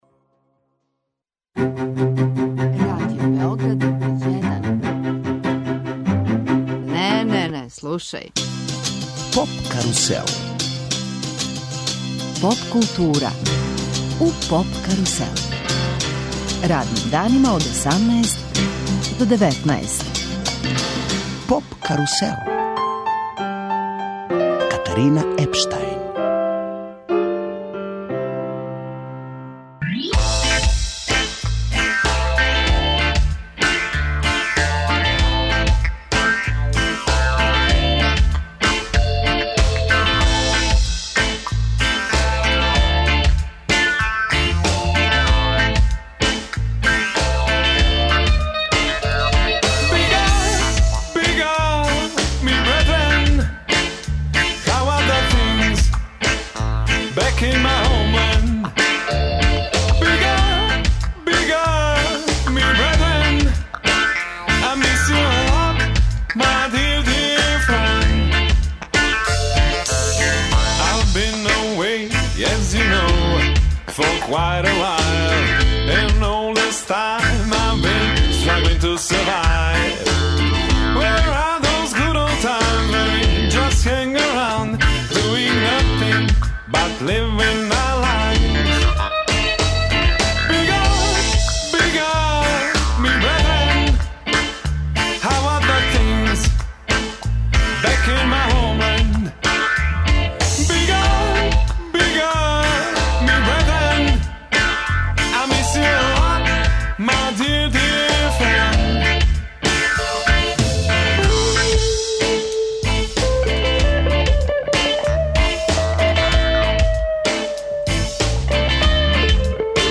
У студио Радио Београда долазе непосредно пред београдски концерт.